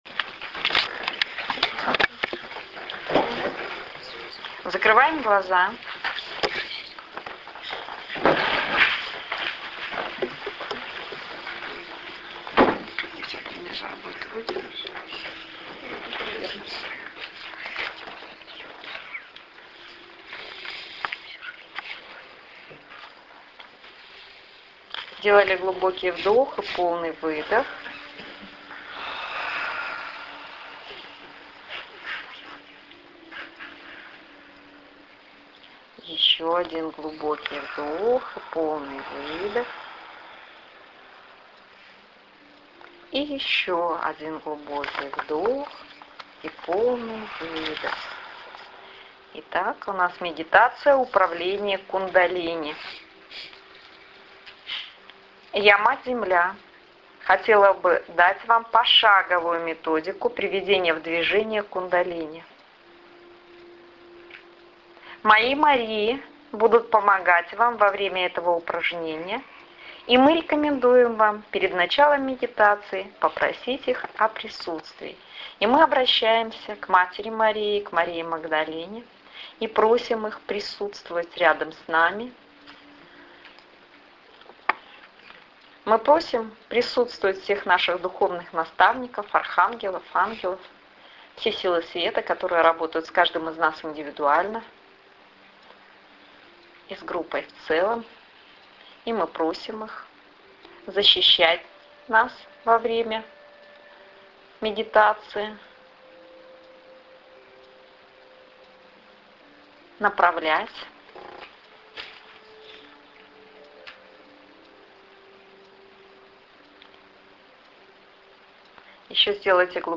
Управление Кундалини аудио медитация Предлагаю вам удивительную медитацию от имени Матери Земли "Управление Кундалини". Я, Мать Земля, хотела бы дать вам пошаговую методику приведенияв движение Кундалини.